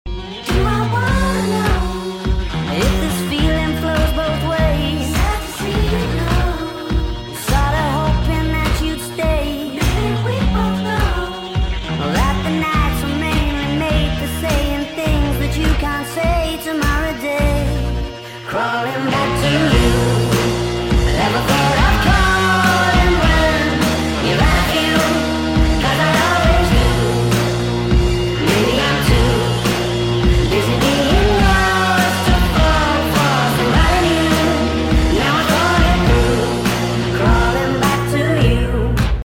sped up !